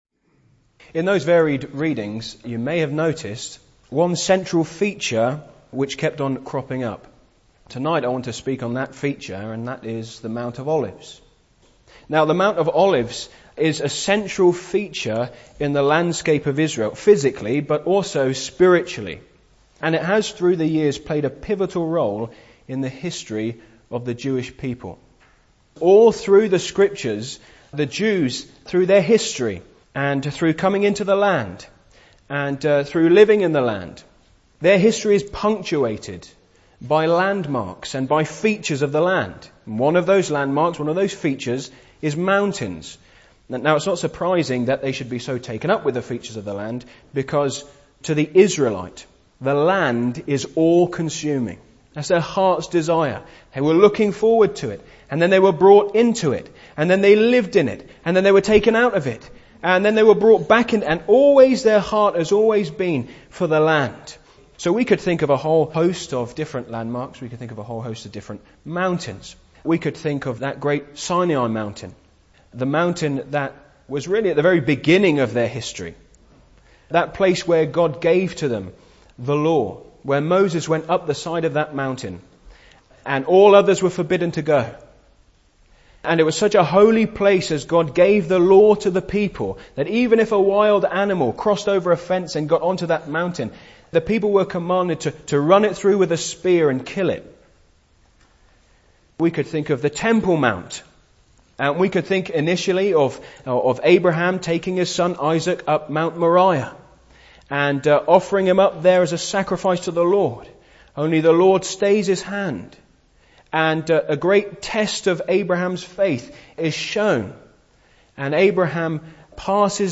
Individual sermons on the Lord Jesus